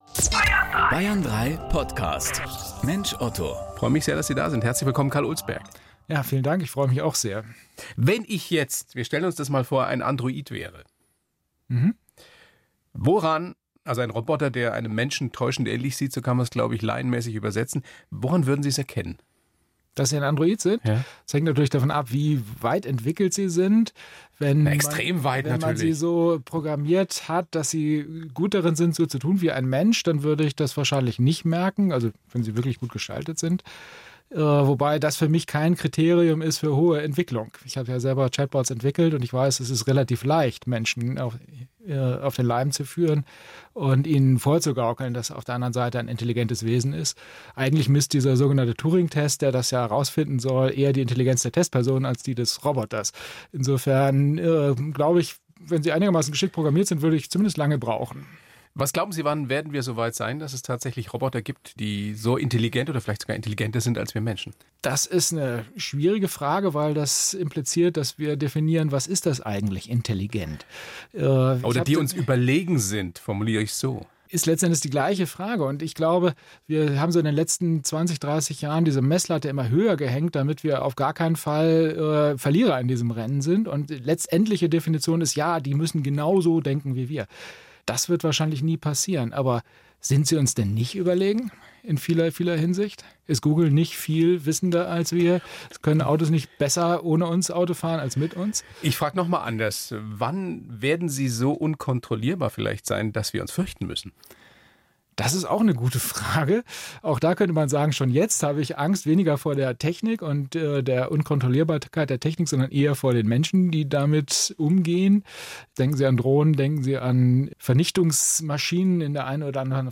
Radio-Talkshow "Mensch, Otto